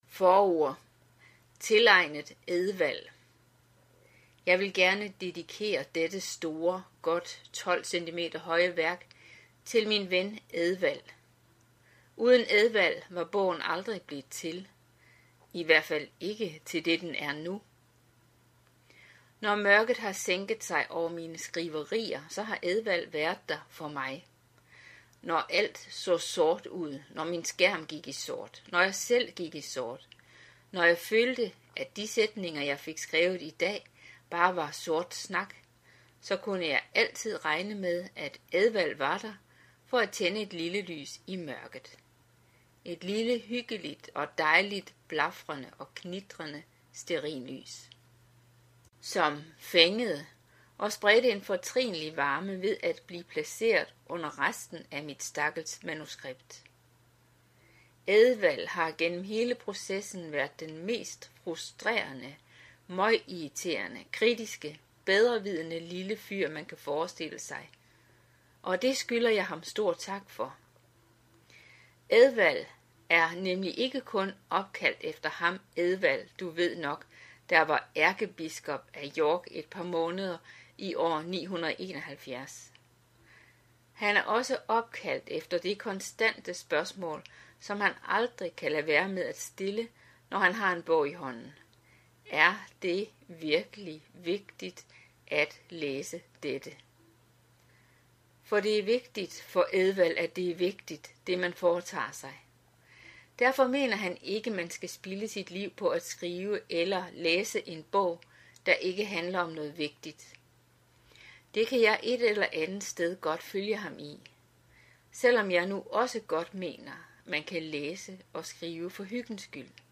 Hør et uddrag af Trosforsvar for teenager Trosforsvar for teenagere Format MP3 Forfatter Henrik Gren Hansen Bog Lydbog E-bog 74,95 kr.